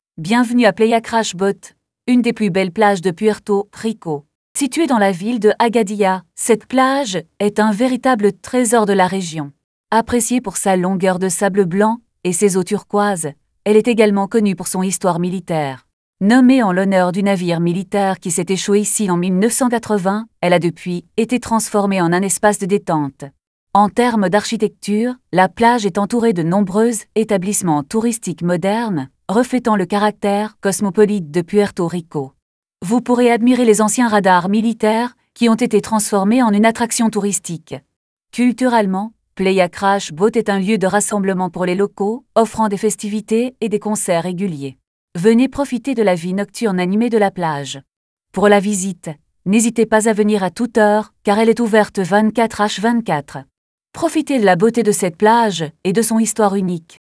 karibeo_api / tts / cache / 3484b7665b09985b0dd701a89db52dfc.wav